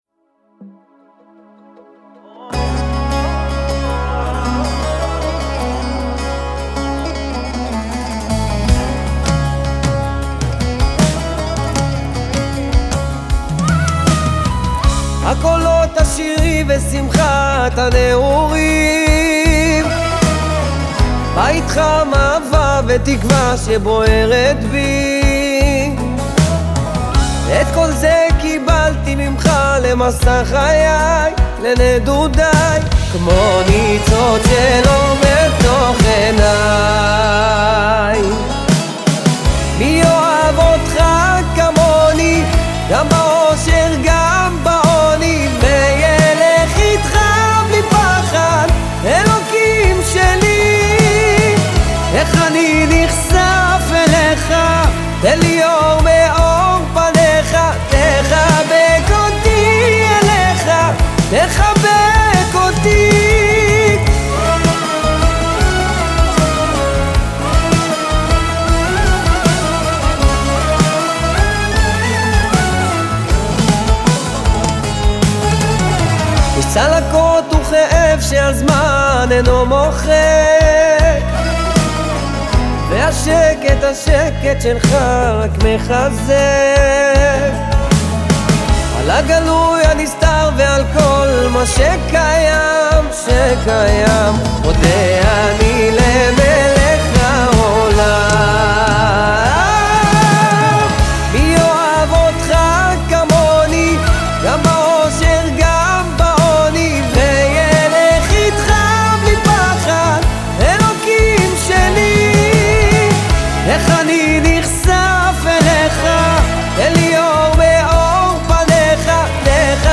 מקהלה
גיטרות
בס